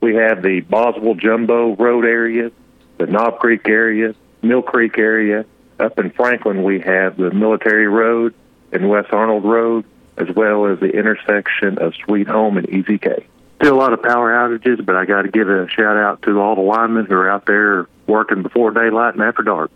Izard County Sheriff Charley Melton spoke with KTLO, Classic Hits and the Boot News Monday morning to give an update on conditions and coming relief to areas hit by the storms in Izard County.